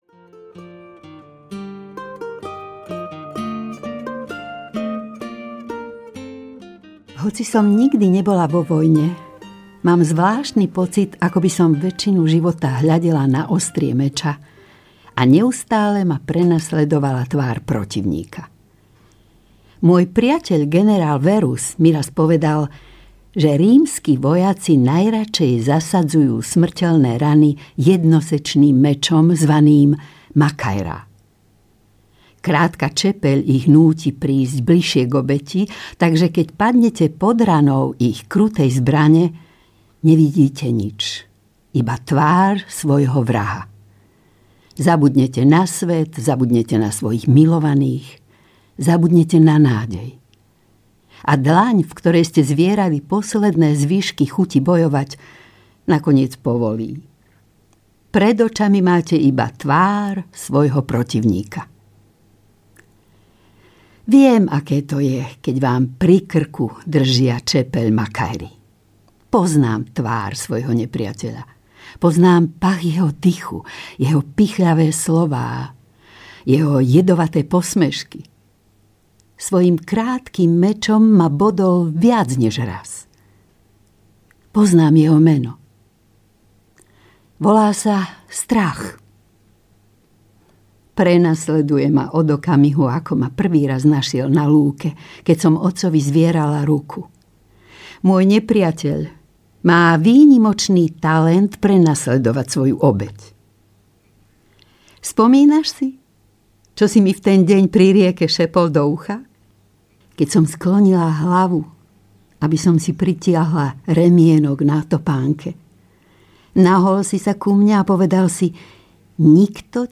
Anjelský chlieb audiokniha
Ukázka z knihy